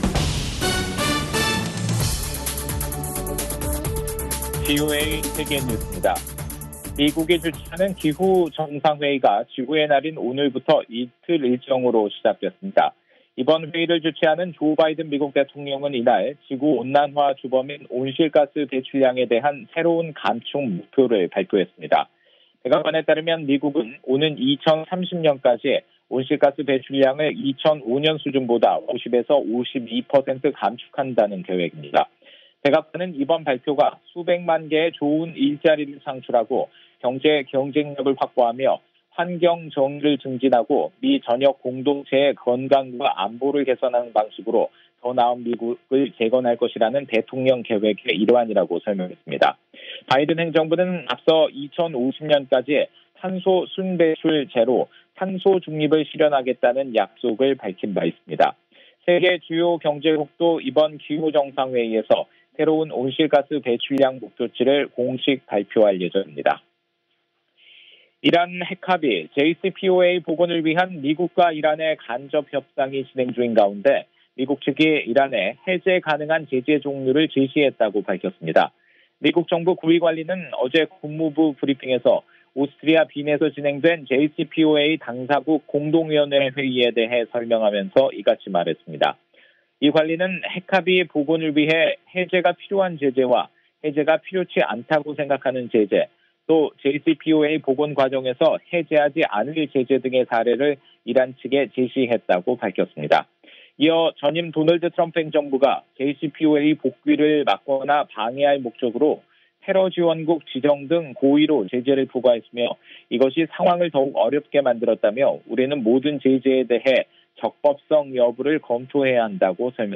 VOA 한국어 간판 뉴스 프로그램 '뉴스 투데이', 2021년 4월 22일 3부 방송입니다. 미국 국무부는 5월로 예정된 문재인 한국 대통령의 미국 방문은 동맹의 중요성을 보여주는 것이라고 강조했습니다. 미국 국제종교자유위원회가 북한을 종교자유 특별우려국으로 재지정할 것을 권고했습니다. 미-일 정상이 최근 북한의 일본인 납치 문제에 대한 협력을 거듭 다짐한 가운데, 워싱턴에서는 북한에 억류된 한국인들의 안전을 우려하는 목소리가 높습니다.